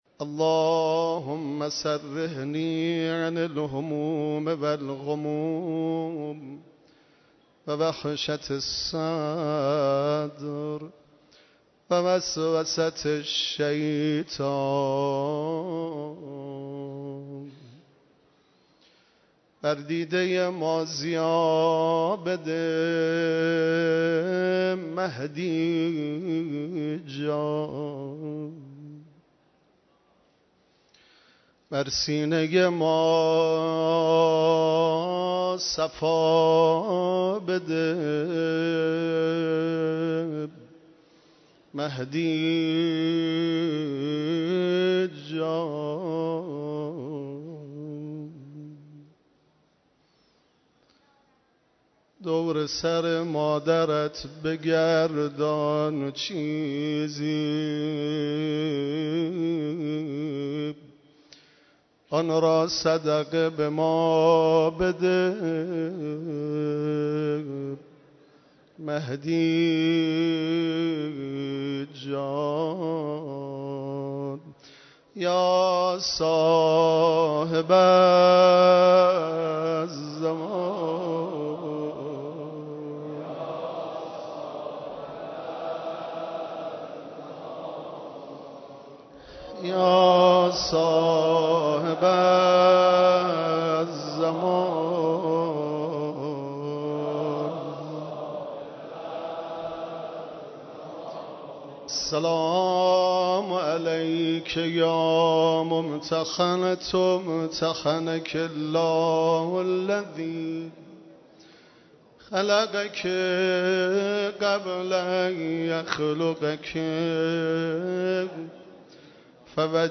در اولین شب عزاداری ایام فاطمیه؛
دریافت 0 bytes دریافت 0 bytes گروه فیلم و صوت مشرق- نخستین شب مراسم عزاداری شهادت حضرت زهرای مرضیه سلام الله علیها پنج شنبه شب 20 اسفند 1394 با حضور رهبر معظم انقلاب و هزاران نفر از اقشار مختلف مردم و همچنین شخصیت های کشوری و لشکری در حسینیه امام خمینی(ره) برگزار شد .